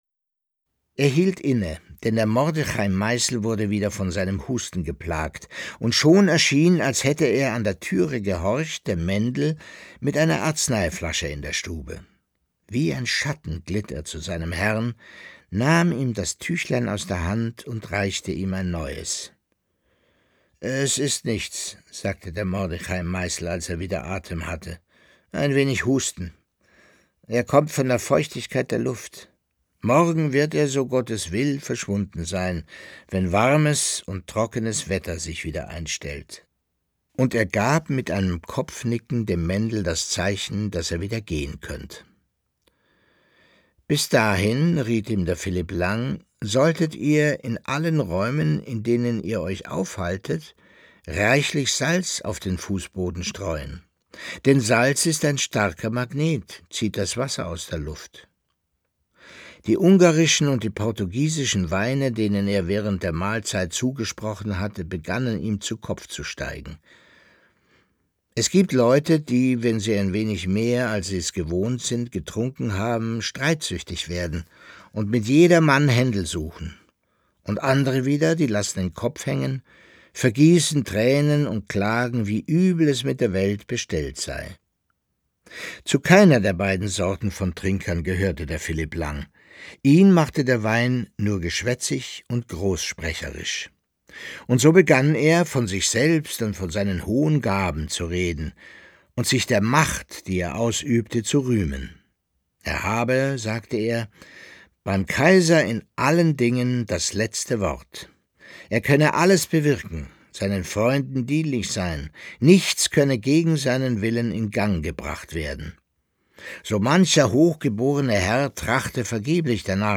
Leo Perutz: Nachts unter der steinernen Brücke (23/25) ~ Lesungen Podcast